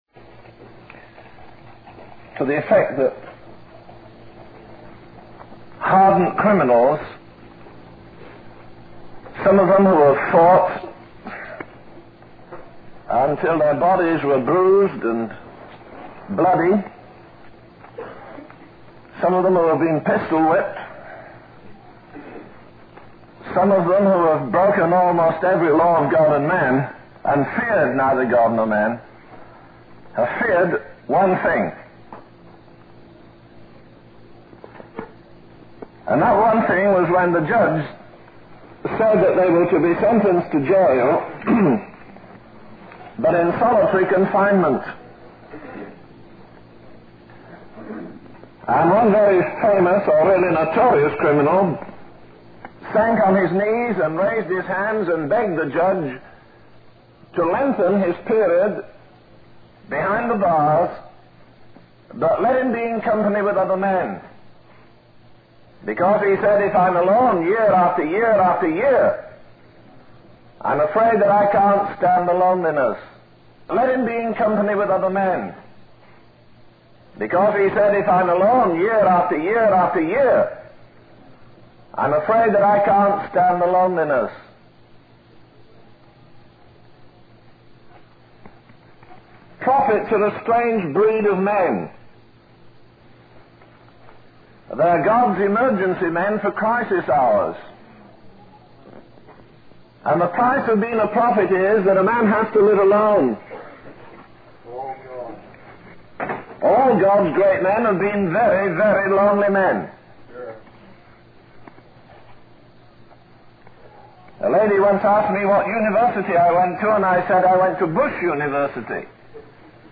In this sermon, the preacher highlights the historical context of the Israelites' captivity under Pharaoh for 400 years and their subsequent periods of captivity. He emphasizes that despite these experiences, the Israelites failed to learn their lesson and continued in ritualism and formality without God's intervention. The preacher draws a parallel to America, suggesting that if God were to allow 400 years of darkness, the nation would not survive.